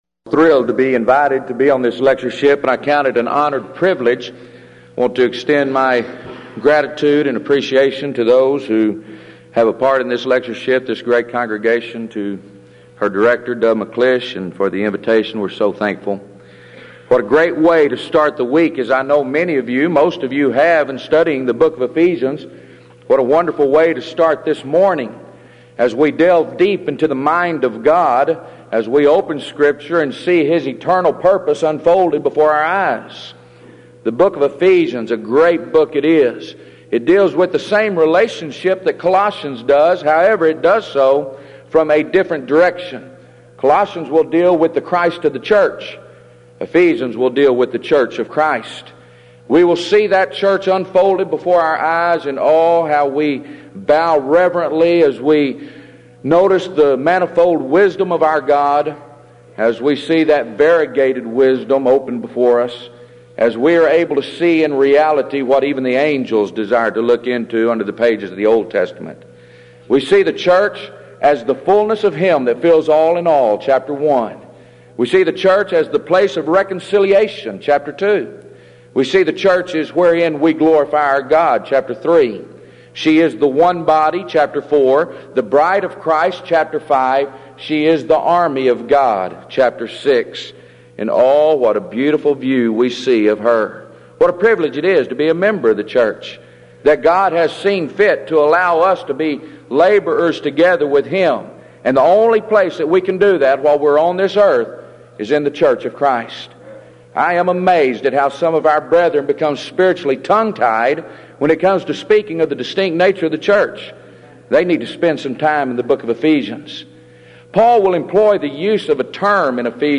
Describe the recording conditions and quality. Event: 16th Annual Denton Lectures Theme/Title: Studies In Ephesians